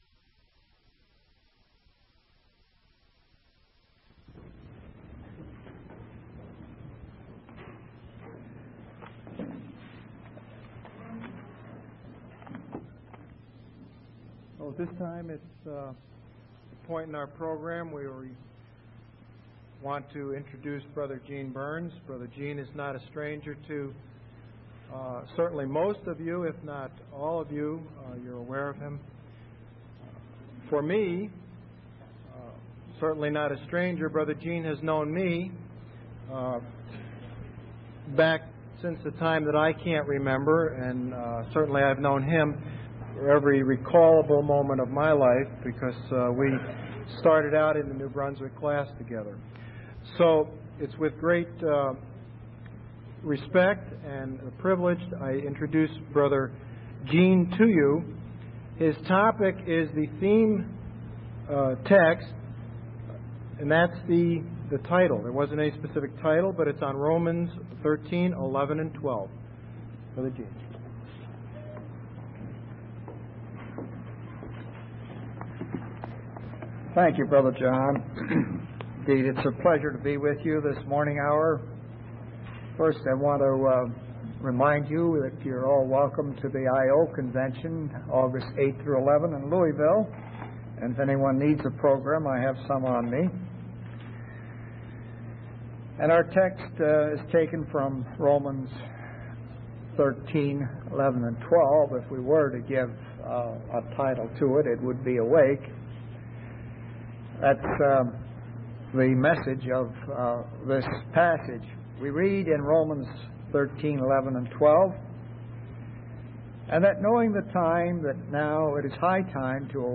From Type: "Discourse"
Given at Indiana/Ohio Convention 1997